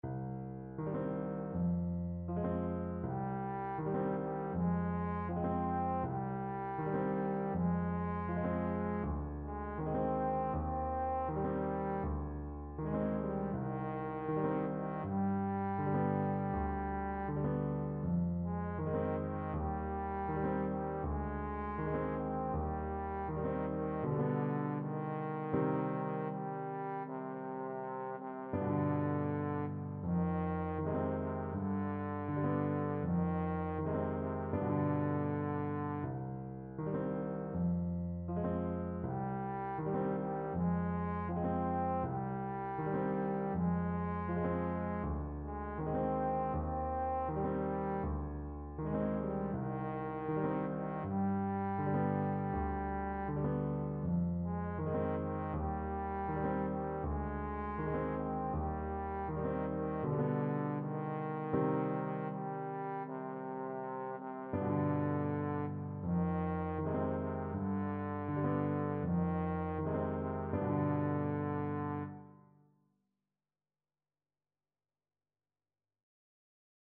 Trombone
Traditional Music of unknown author.
4/4 (View more 4/4 Music)
C minor (Sounding Pitch) (View more C minor Music for Trombone )
Andante